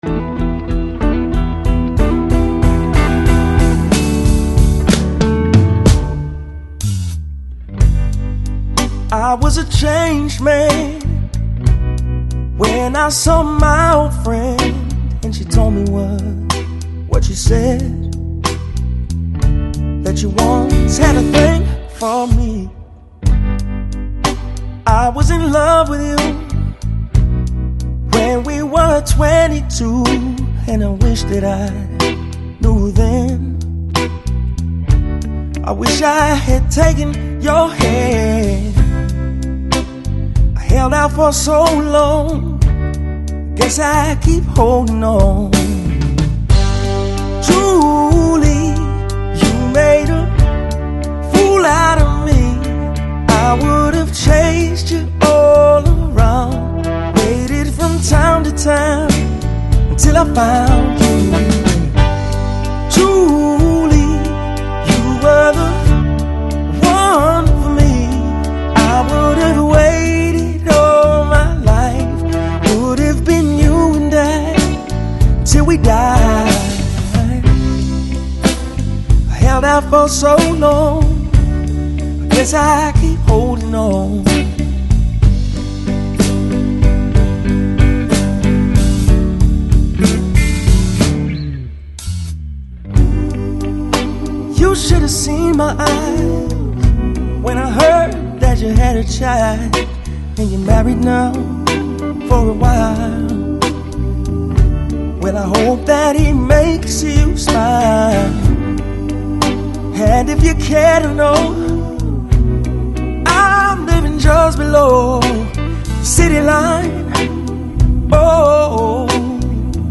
Жанр: Downtempo, Chill Out, Nu Soul, Jazz